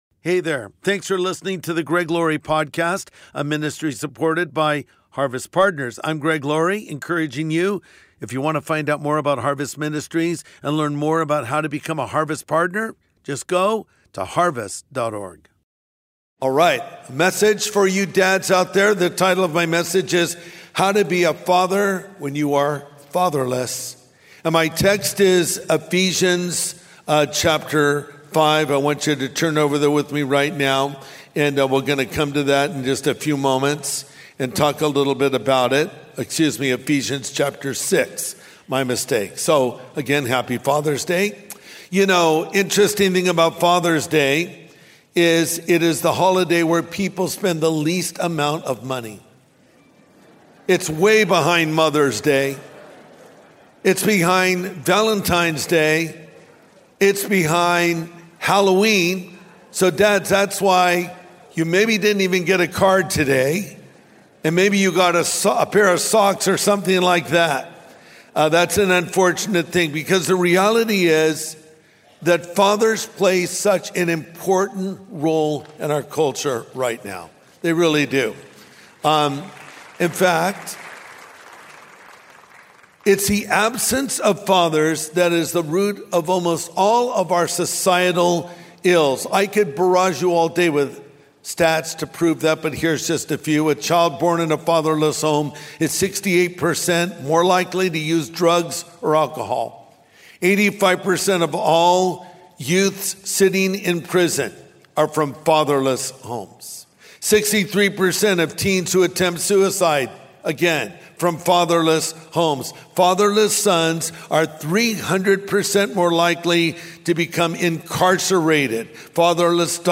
How to Be a Father When You're Fatherless | Sunday Message